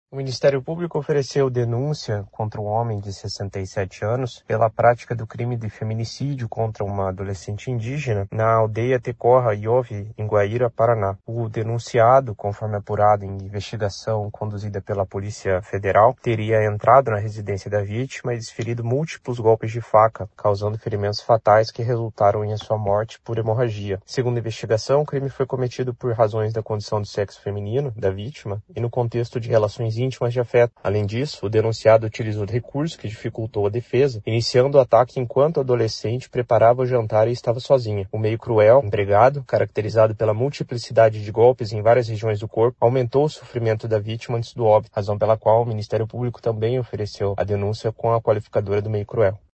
Além da condenação por feminicídio, o MPPR pede que o réu pague indenização à família da vítima no valor de R$ 50 mil. Ouça o que diz o promotor de Justiça Renan Guilherme Góes de Lima: